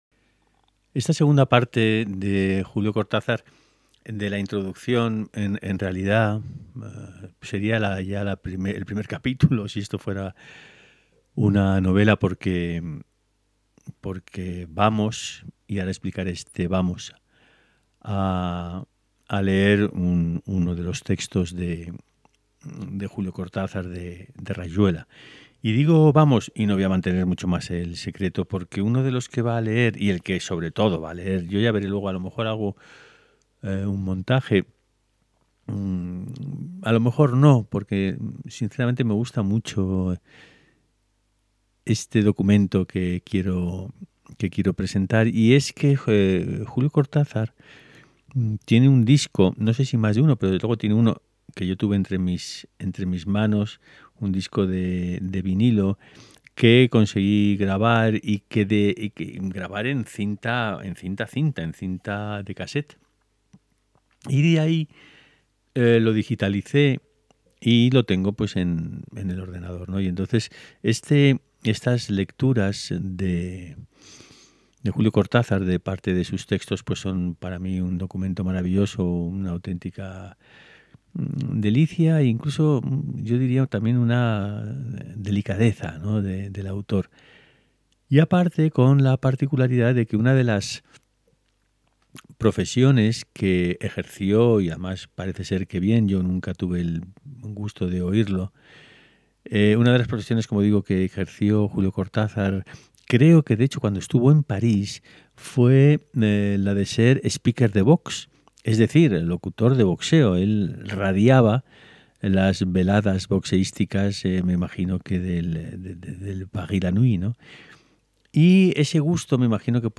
Lecturas en alta voz